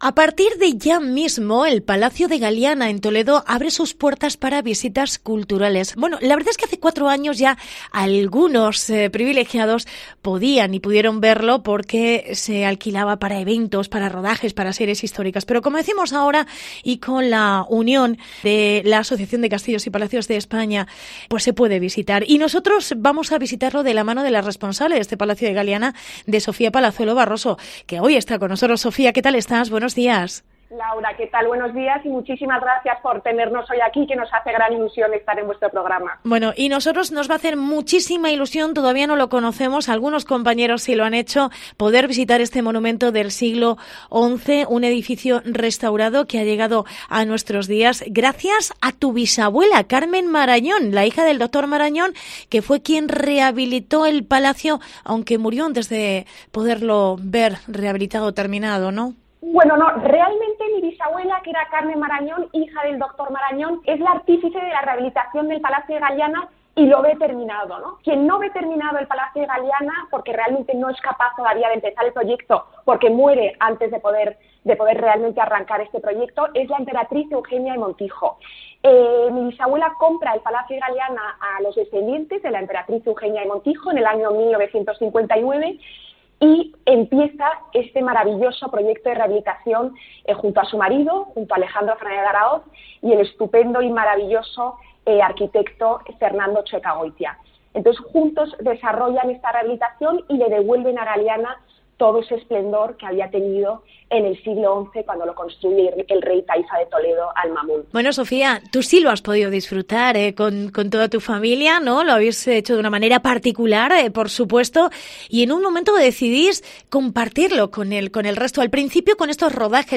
Entrevista Sofia Palazuelo